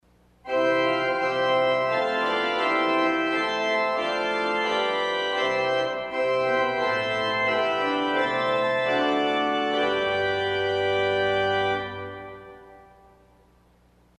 The sound clips were recorded using a Schoeps MK 21 microphone feeding a Zoom H4 digital recorder, recording directly to 160 bit mp3 format.
III Cymbel   Burger & Shafer 1972; Möller pipes arpeggio
CymbelStAnne.mp3